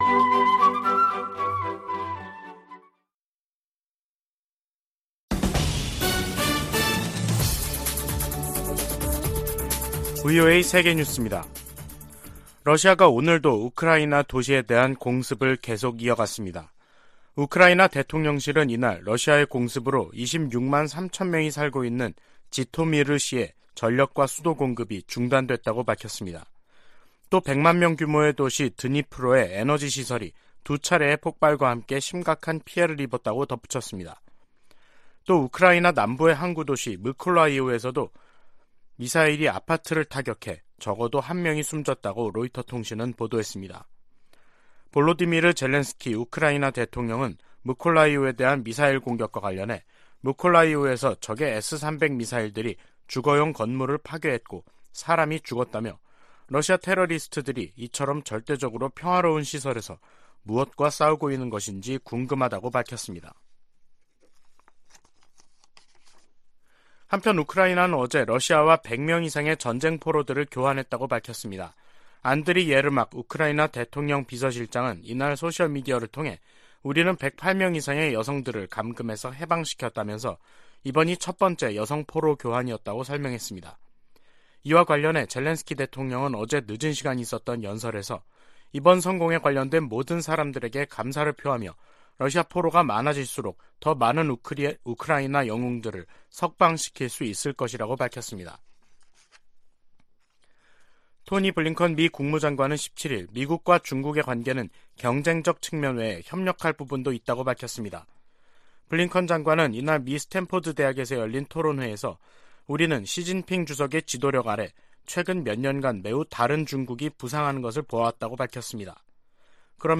VOA 한국어 간판 뉴스 프로그램 '뉴스 투데이', 2022년 10월 18일 2부 방송입니다. 북한의 잇따른 도발은 무시당하지 않겠다는 의지와 강화된 미한일 안보 협력에 대한 반발에서 비롯됐다고 토니 블링컨 미 국무장관이 지적했습니다. 필립 골드버그 주한 미국대사는 전술핵 한반도 재배치론에 부정적 입장을 분명히 했습니다. 북한의 잇단 미사일 발사로 긴장이 고조되면서 일본 내 군사력 증강 여론이 강화되고 있다고 미국의 일본 전문가들이 지적했습니다.